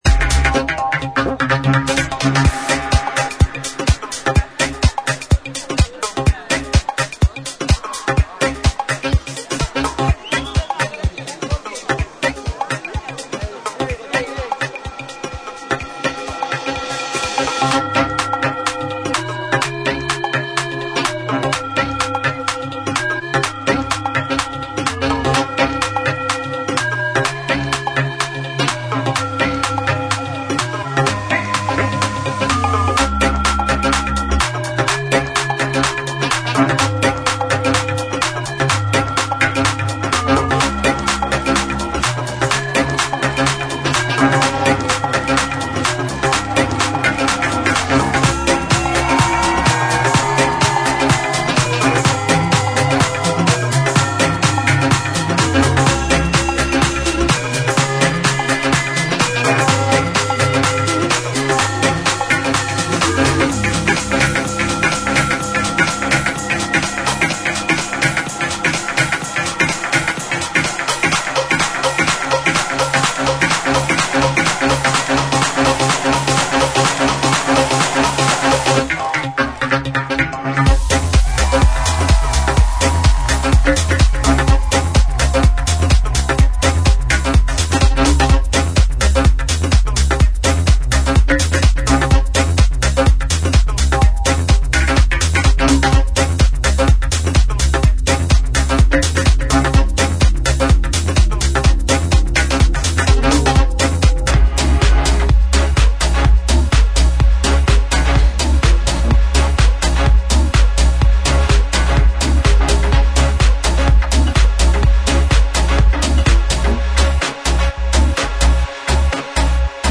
TECHNO/HOUSE Online vinyl record shop ご注文方法
A.original mix